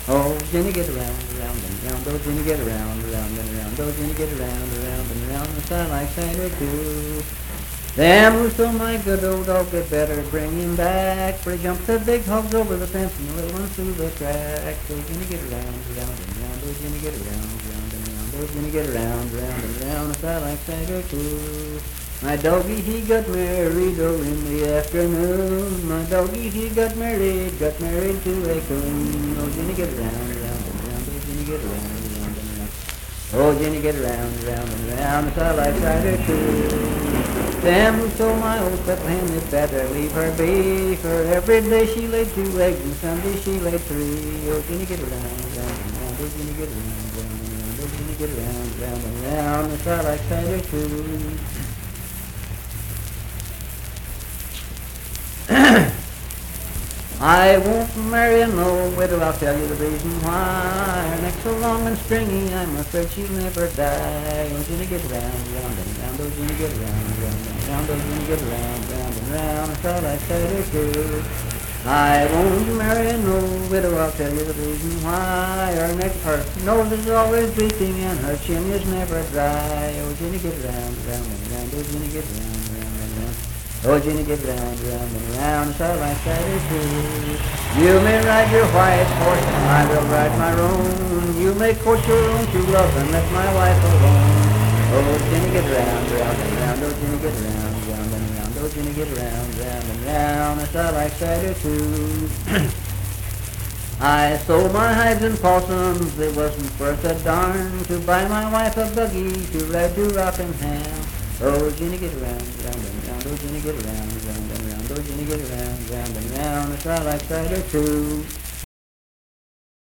Unaccompanied vocal music performance
Verse-refrain 8(8).
Voice (sung)